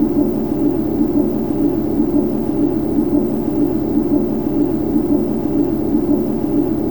IDG-A32X/Sounds/SASA/CFM56/wingfwd/cfm-comb.wav at a097f45abd1d3736a5ff9784cdaaa96ad1f1bef2
Edit sounds to remove noise and make them loop better